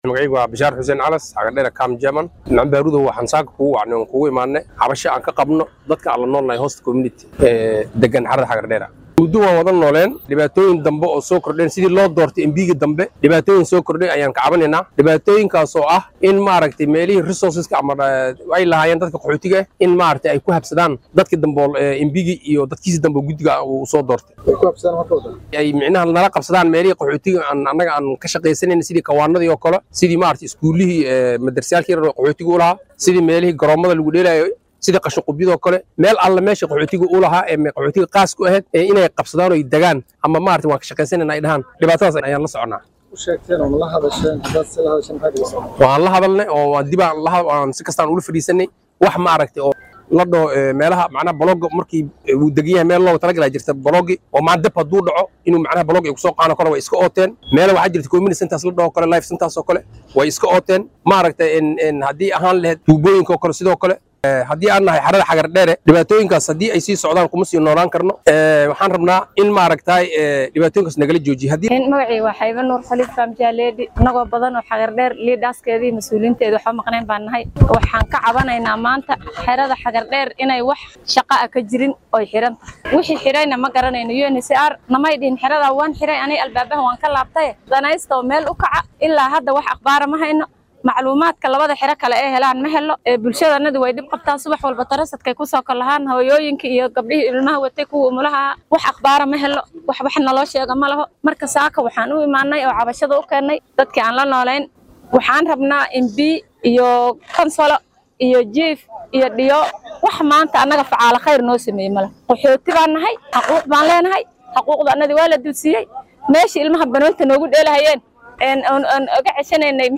Qaar ka mid ah qaxootiga xeradaasi oo la hadlay warbaahinta Star ayaa dareenkooda sidan u muujiyey.